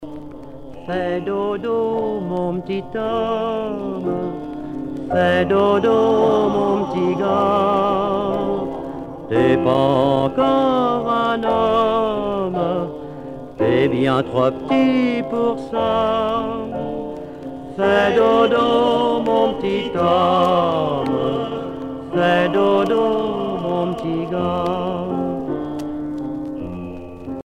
enfantine : berceuse
Pièce musicale éditée